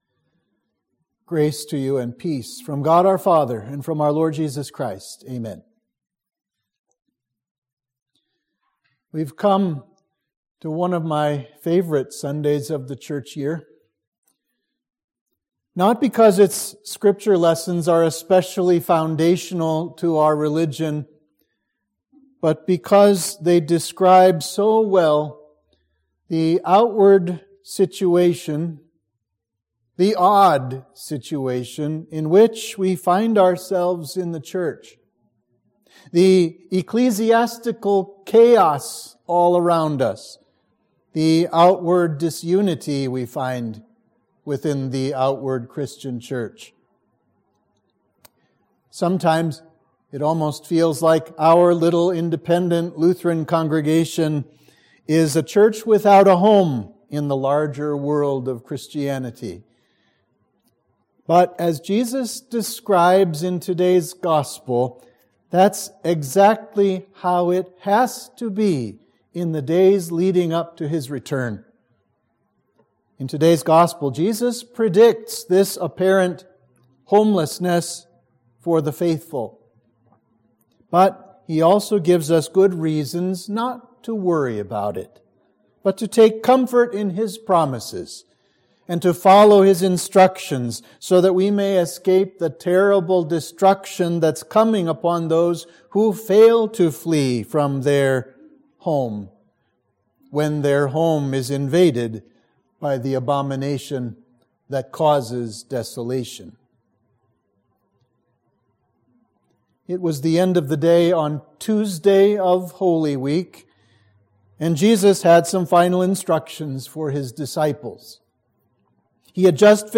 Sermon for Trinity 25 – Third-to-last Sunday